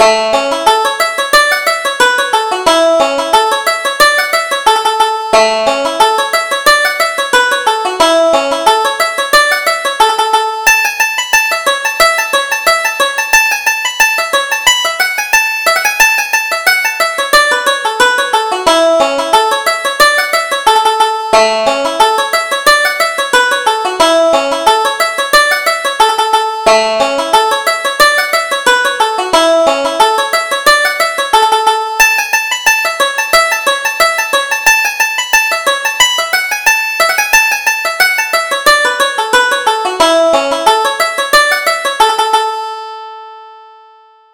Reel: Young Arthur Daly